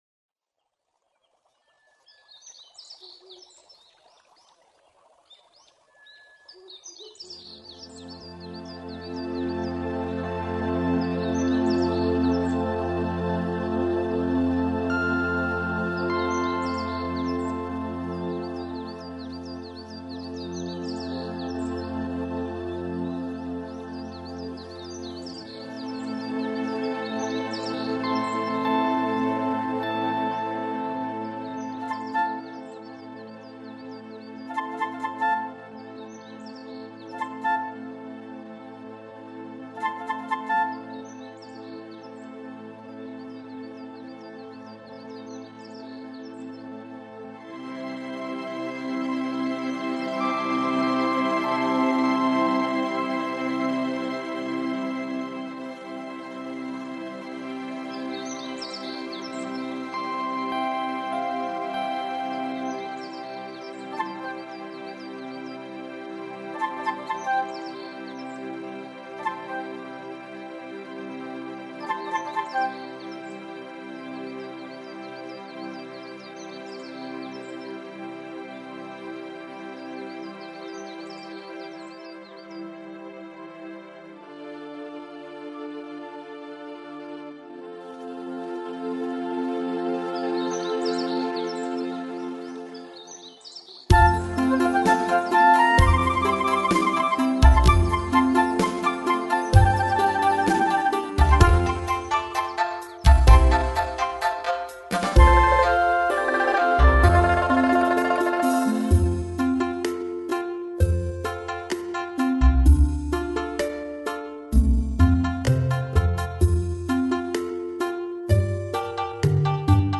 无 调式 : F 曲类